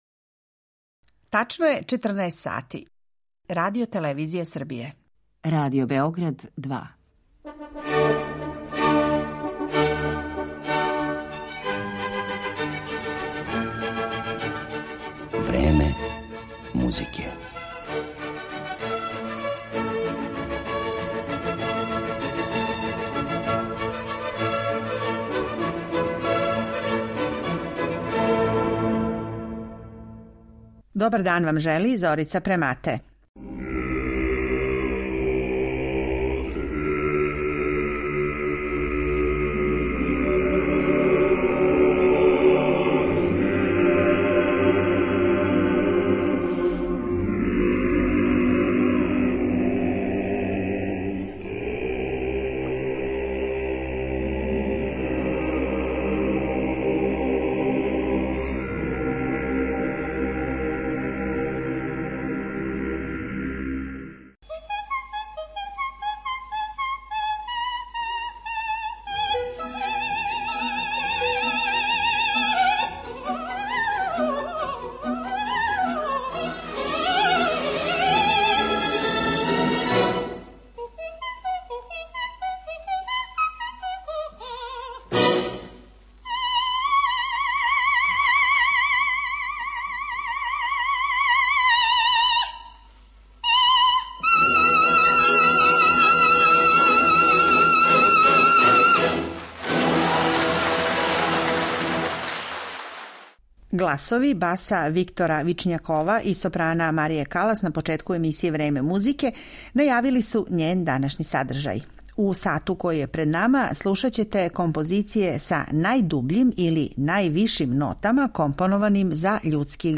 Биће то арије из опера од Монтевердија и Моцарта - до Росинија, Вердија и Делиба.